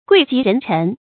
贵极人臣 guì jí rén chén 成语解释 君主时代指大臣中地位最高的人。